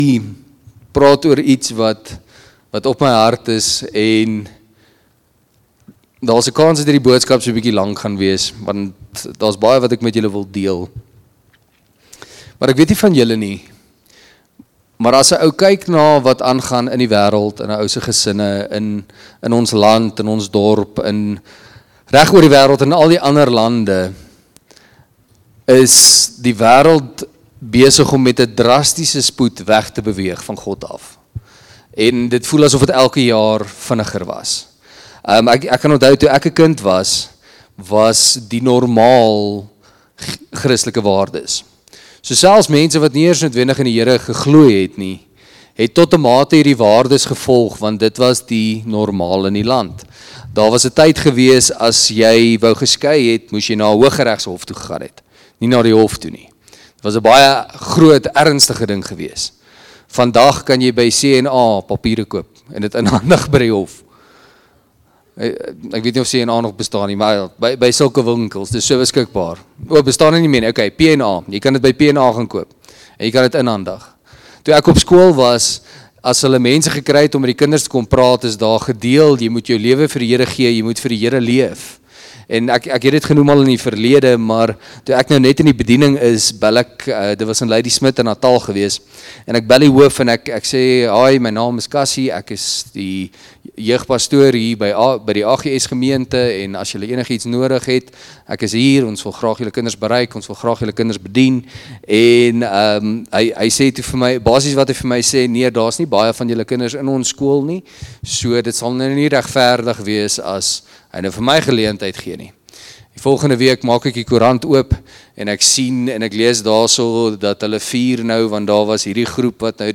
Predikant